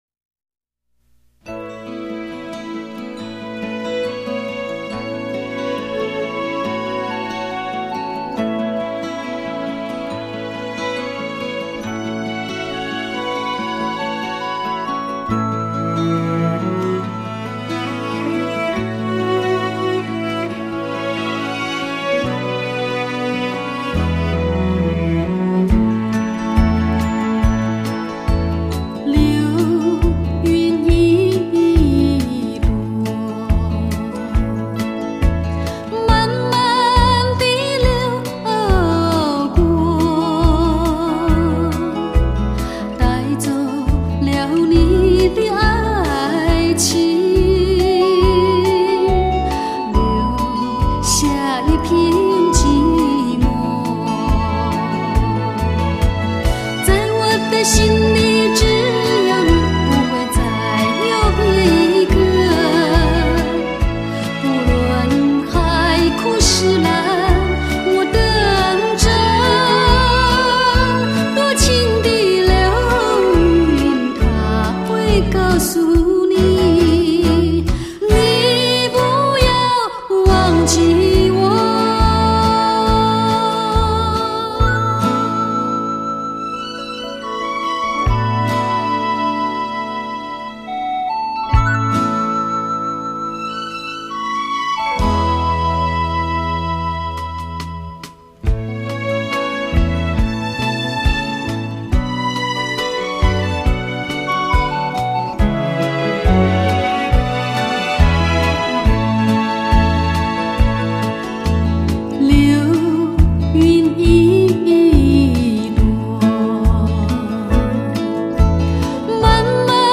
温柔名曲 珍爱一生
新古典的浪漫 后现代的抒情
她的歌，用温柔浅唱 她的歌，在永恒转航